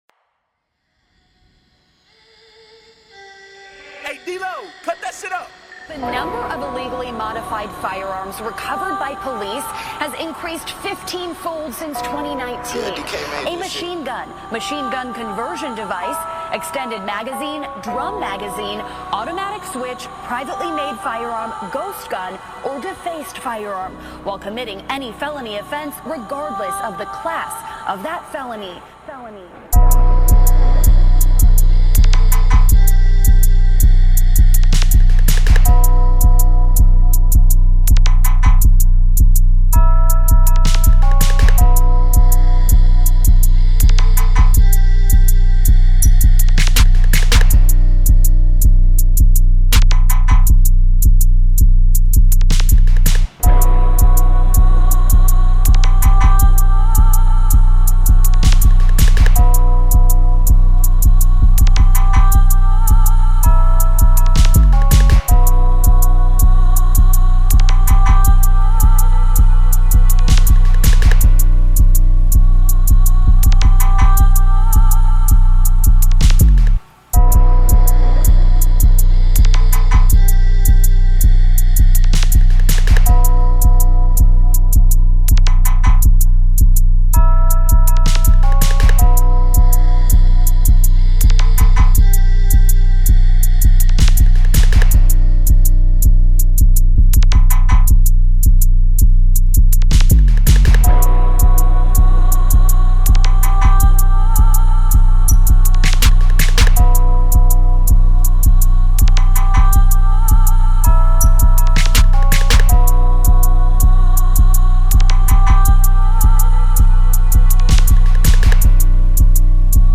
Here's the official instrumental